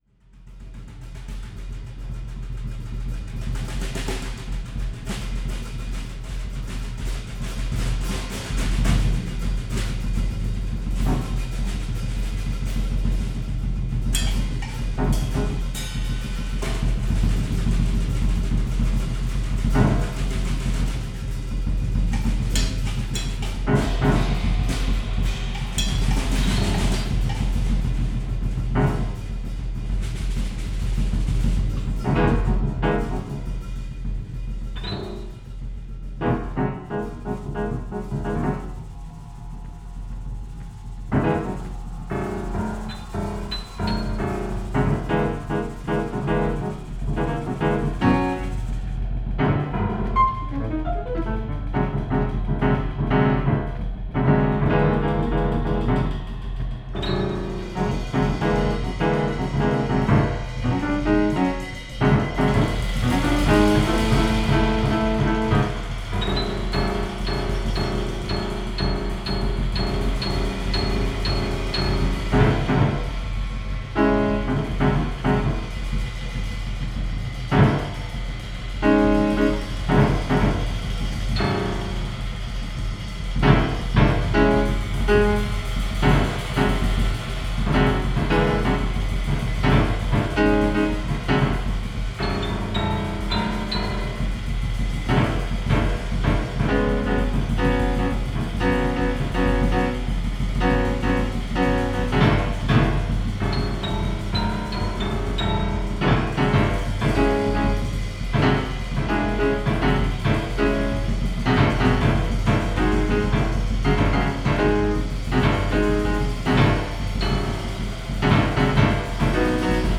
drumset / graphic scores / improvisation /
Théâtre Sévelin 36 – Lausanne
batterie